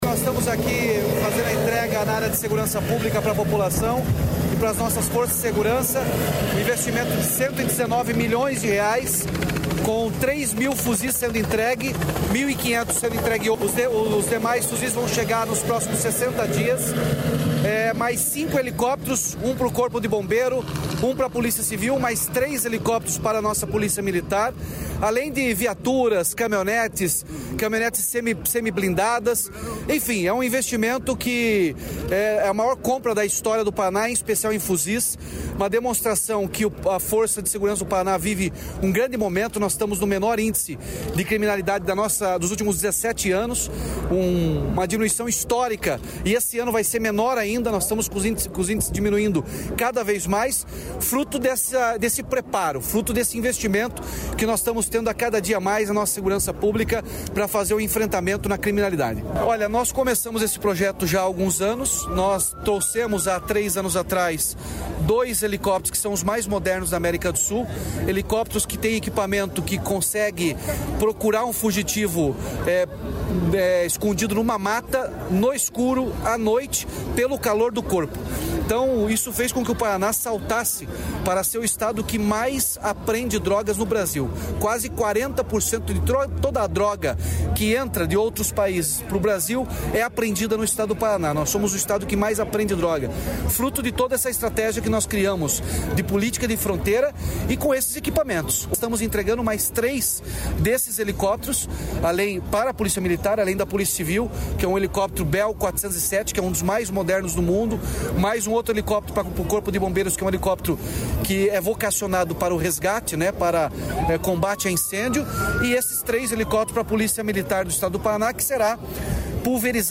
Sonora do governador Ratinho Junior sobre os reforços na Segurança Pública